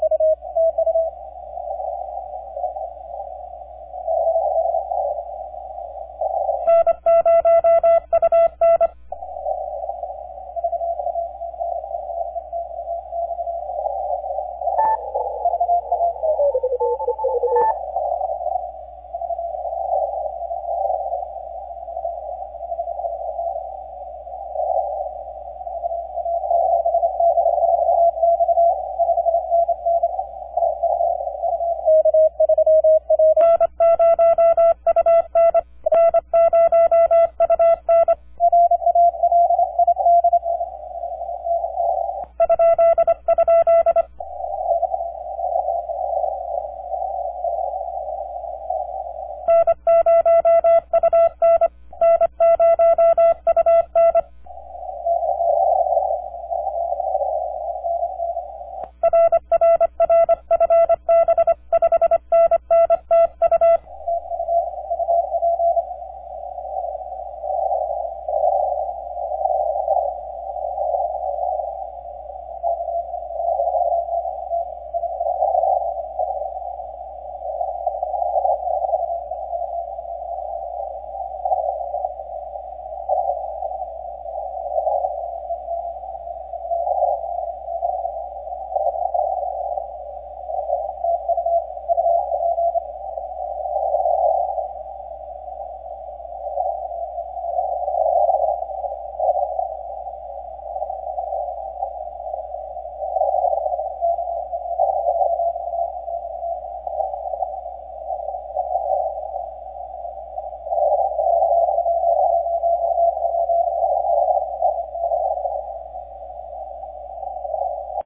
3Y0K 80M CW
Just after 3Y0K’s sunrise this evening, they came out of the mud on 80 CW nicely. Quite a bit of QSB, but workable – that is until they weren’t.
The odd part was for 5 minutes the QRM went on and on and on, but then immediately after my Q there were several minutes where not a single jammer was heard.